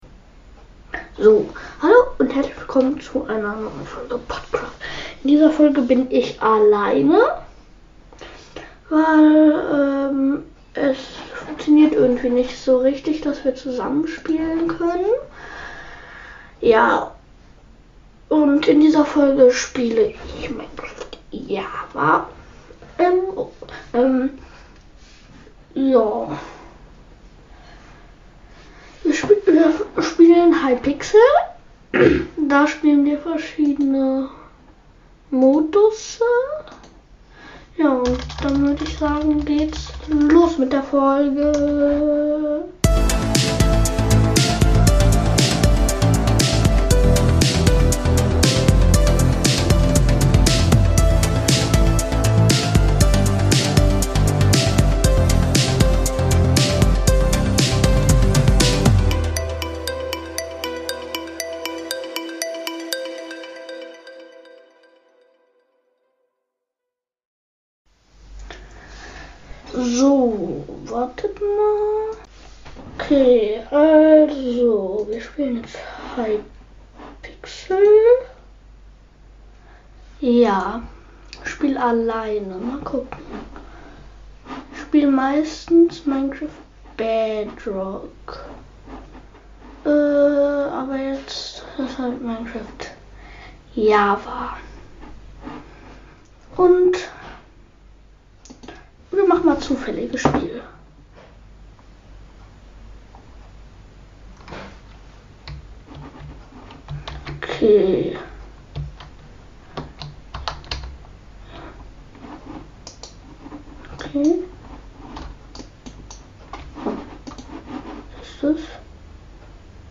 Und wenn die Audioqualität nicht so gut ist dann liegt das daran das unsere Audioeinstellungen auf stereo waren und deswegen hört man halt vielleicht alles doppelt.